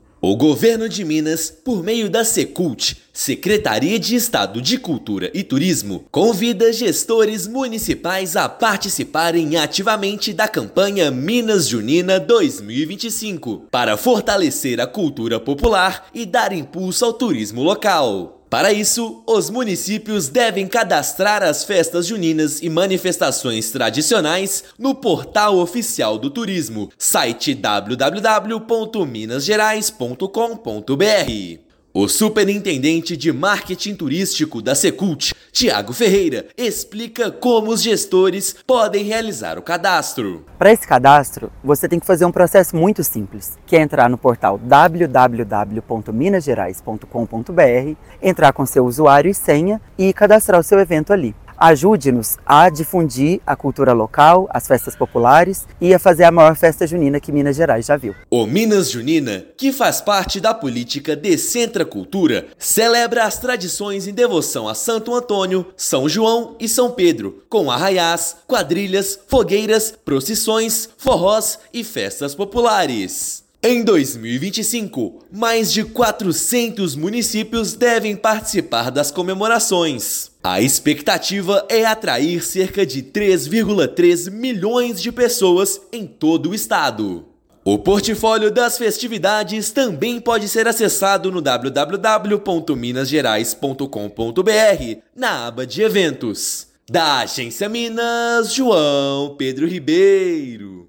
Gestores municipais podem cadastrar eventos no portal oficial do turismo de Minas Gerais e promover os arraiás que movimentam a economia e celebram as tradições pelo estado. Ouça matéria de rádio.